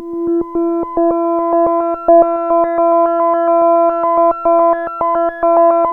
JUP 8 E5 11.wav